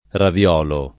raviolo [ ravi- 0 lo ]